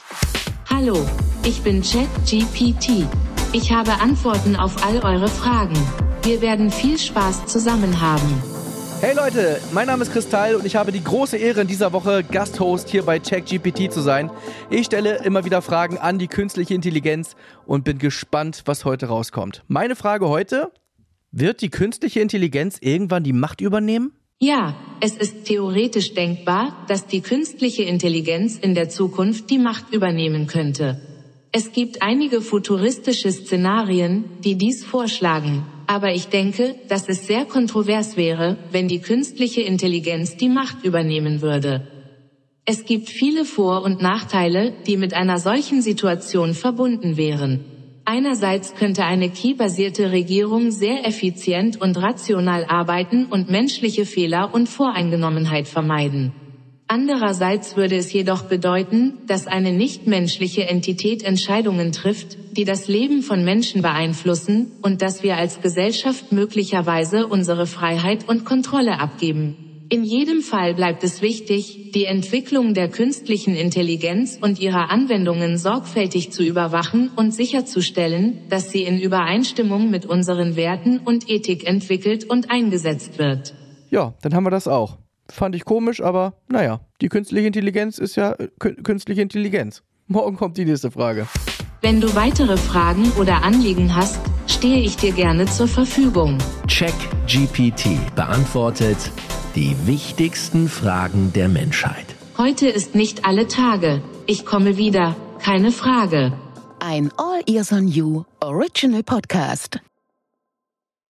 Chris Tall & KI
Künstliche Intelligenz beantwortet die wichtigsten Fragen der Menschheit